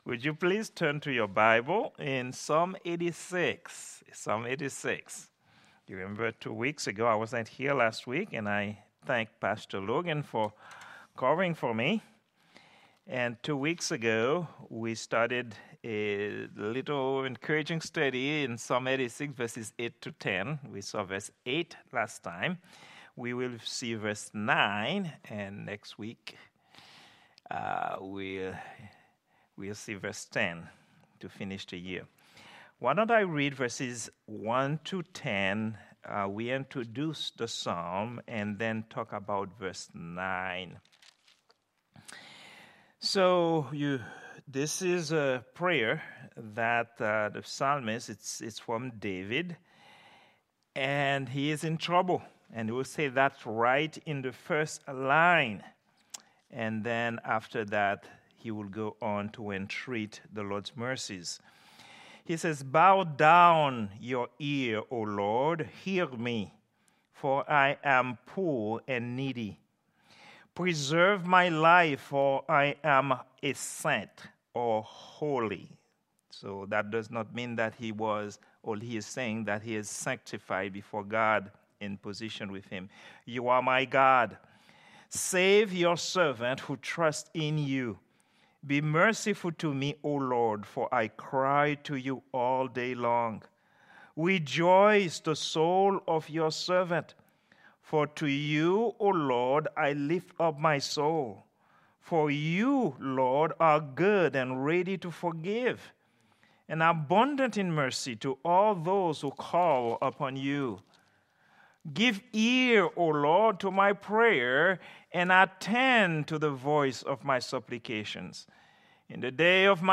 Prayer_Meeting_12_20_2023.mp3